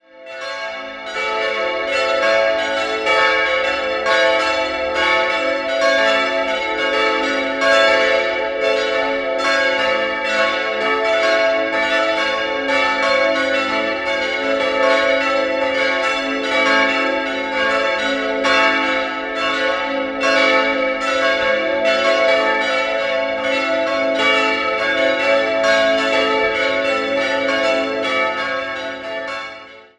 4-stimmiges Geläut: a'-cis''-e''-fis'' Die Glocken wurden im Jahr 1970 von der Gießerei Perner in Passau hergestellt.